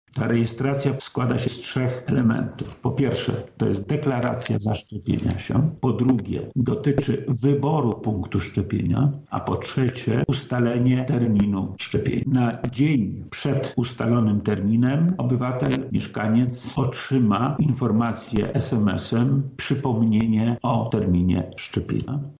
O procedurze rejestracji mówi wojewoda lubelski Lech Sprawka: